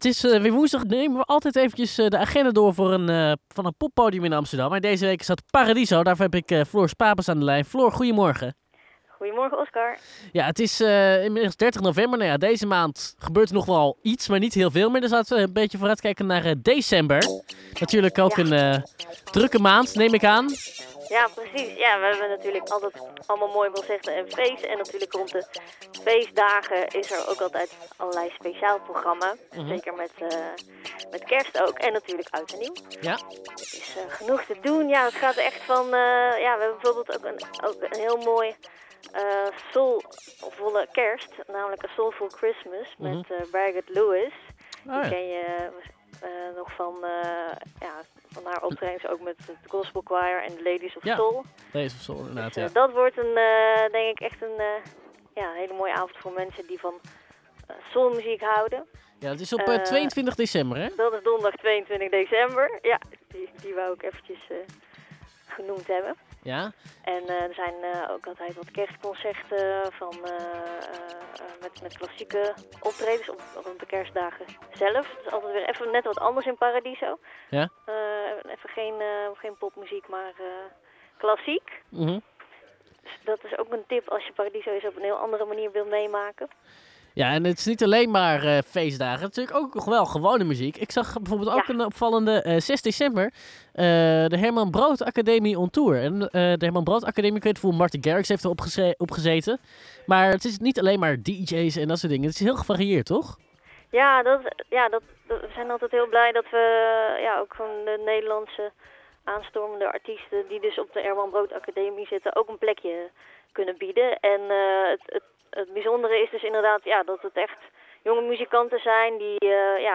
Elke woensdag belt Amsterdam Light met een poppodium in de stad om de agenda door te nemen.